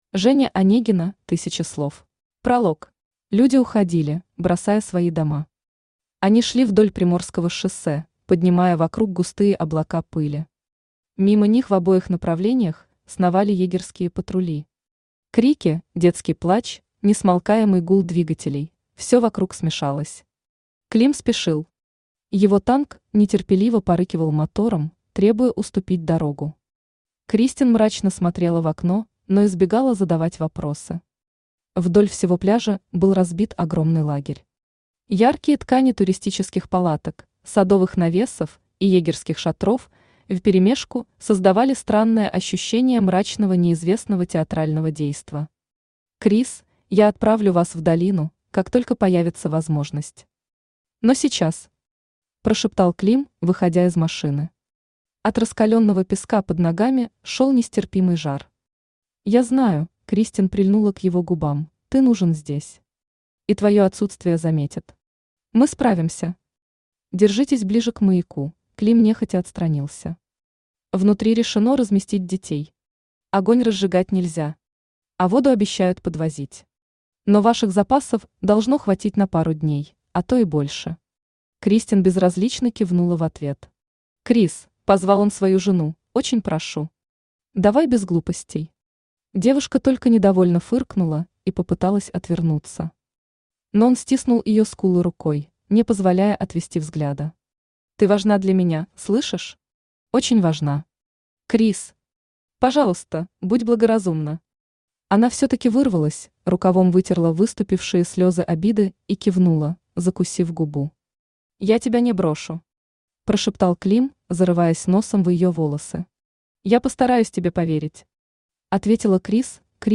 Аудиокнига Тысяча слов | Библиотека аудиокниг
Aудиокнига Тысяча слов Автор Женя Онегина Читает аудиокнигу Авточтец ЛитРес.